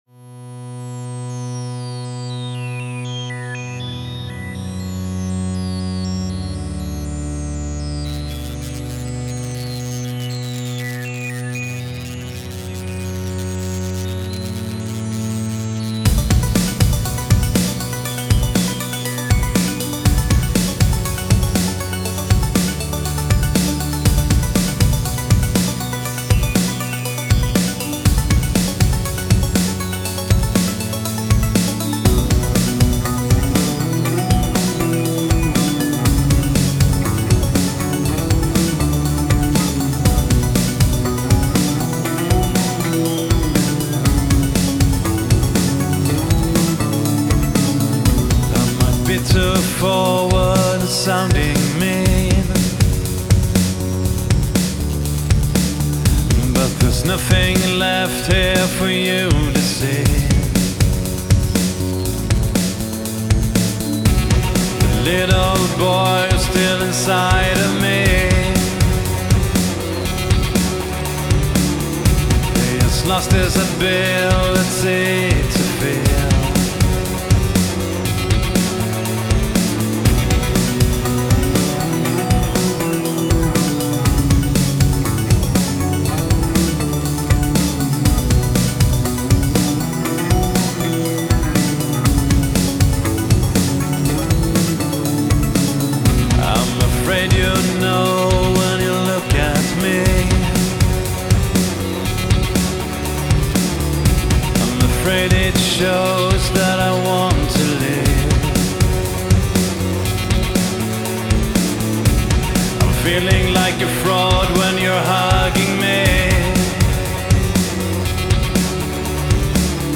Genre: Electronic SpaceSynth.